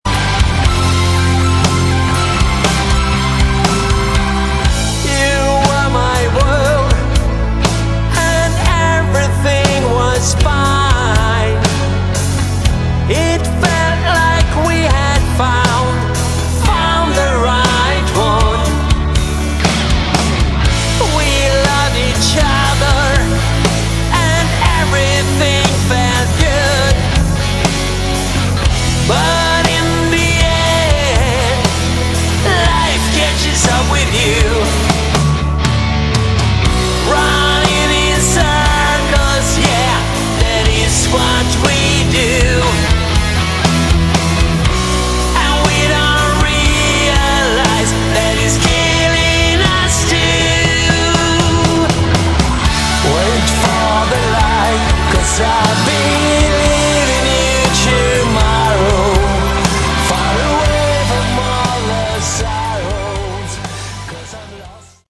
Category: Hard Rock
bass
guitar
vocals
keyboards
drums